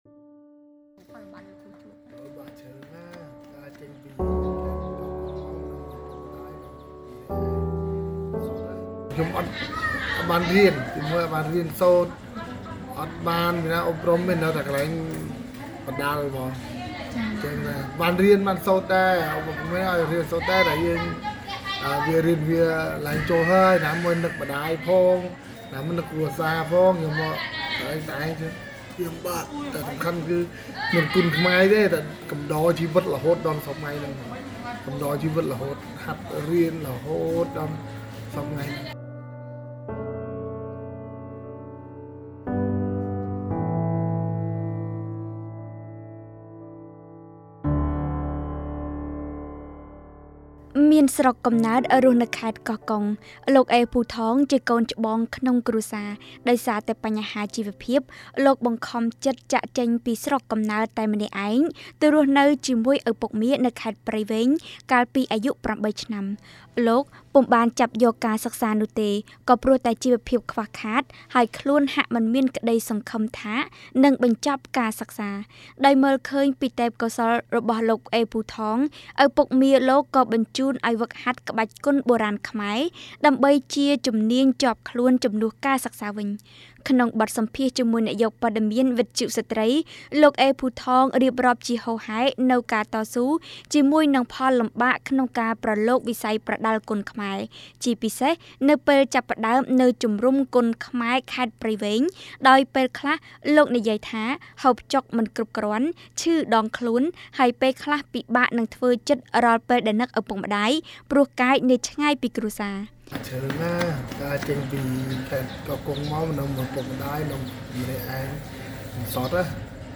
បទយកការណ៍